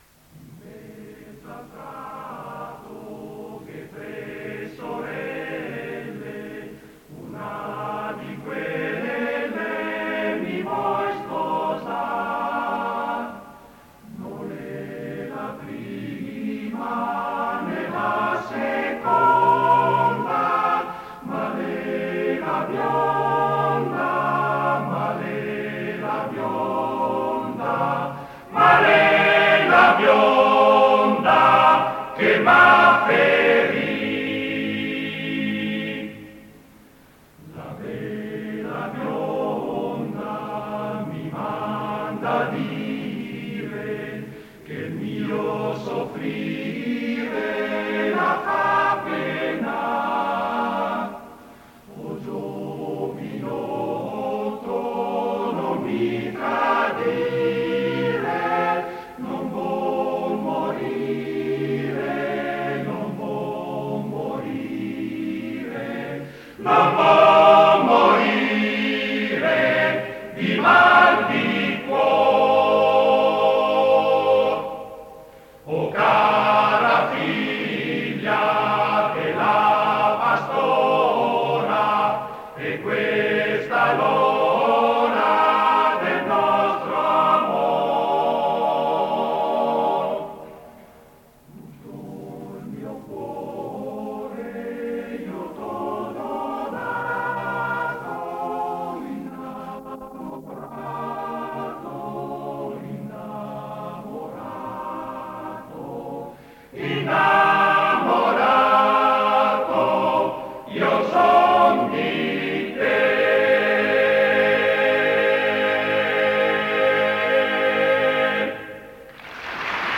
Esecutore: Coro CAI Uget
Fa parte di: Canti di montagna in concerto